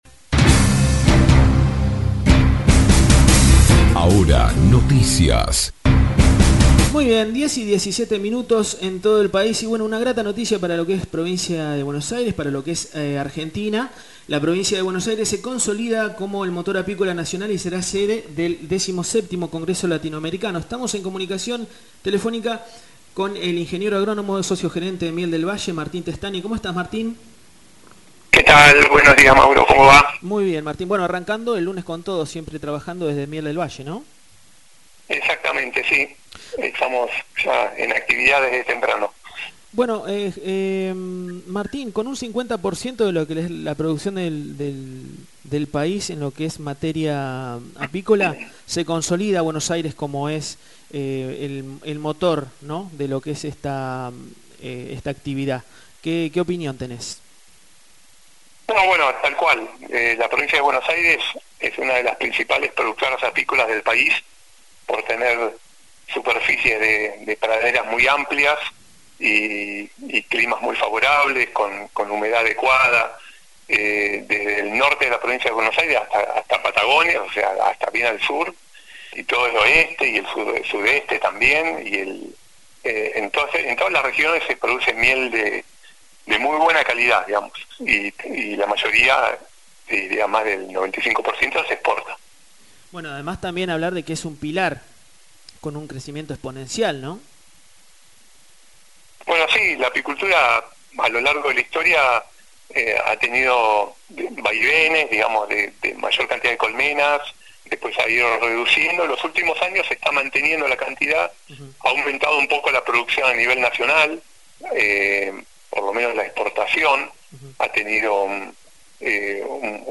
En diálogo